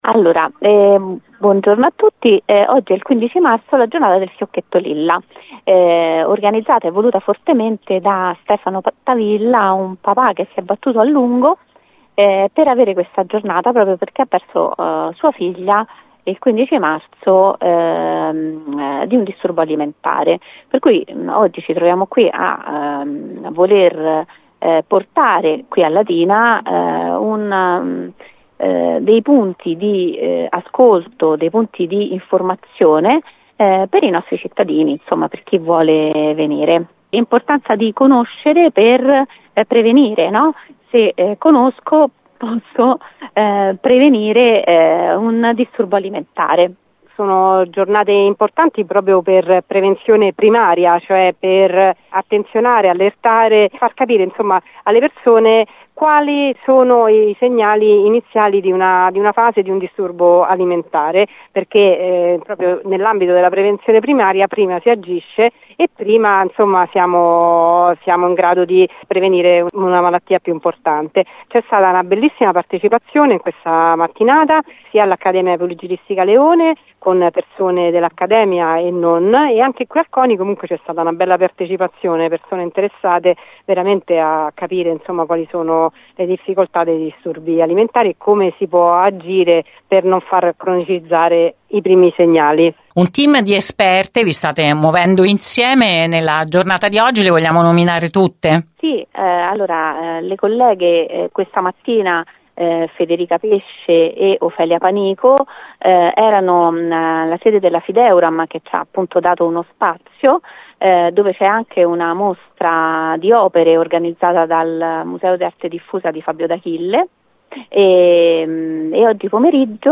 hanno raccontato a Gr Latina